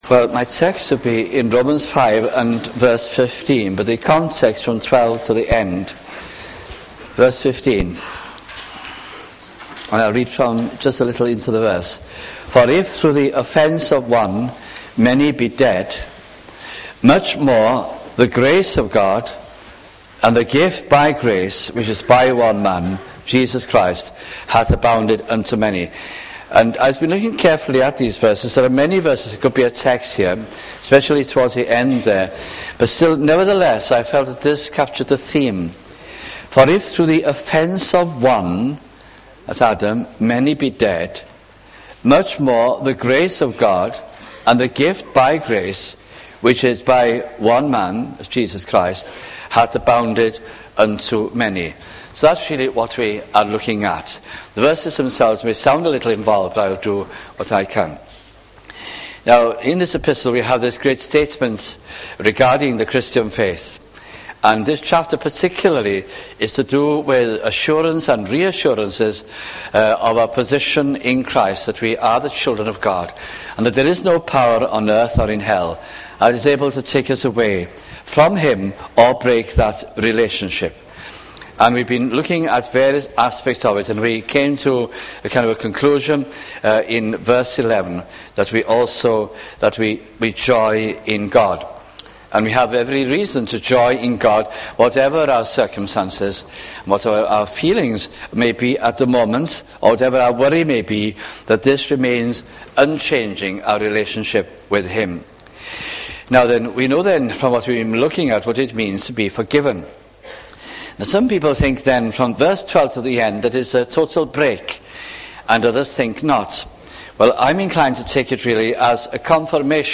» Romans 1996-98 » sunday morning messages